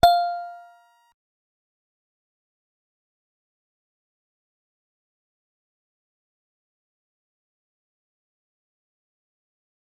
G_Musicbox-F6-mf.wav